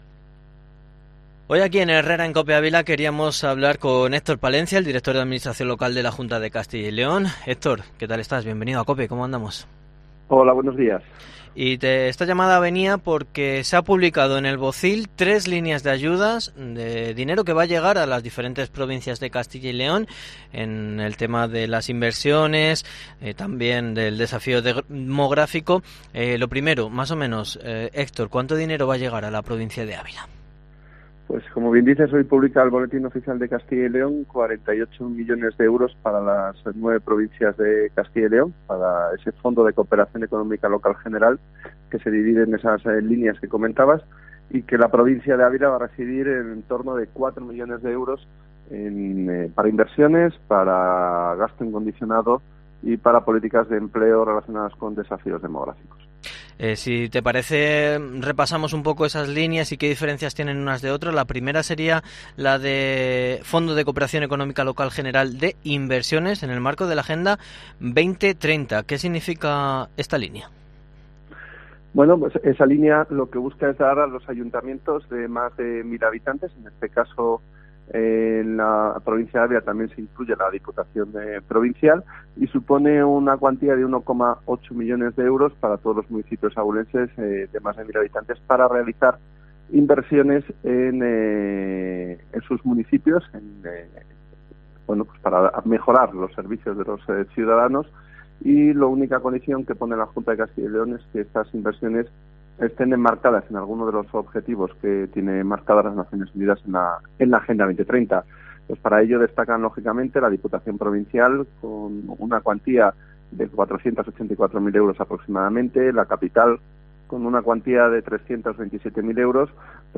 Entrevista con el director de Administración Local de la Junta, Héctor Palencia, en COPE